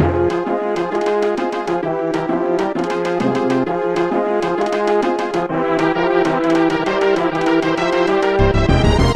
Boss battle theme